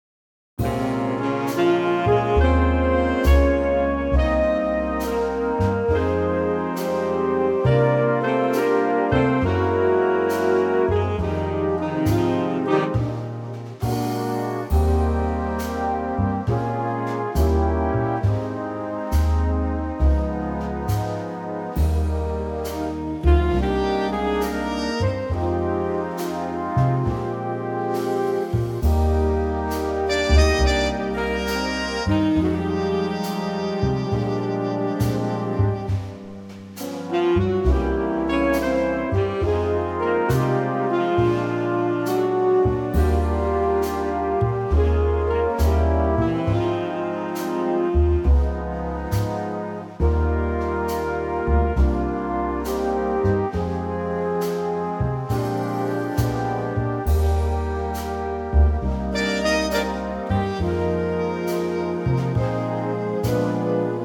key - Eb - vocal range - Bb to D
Stunning mellow Big Band arrangement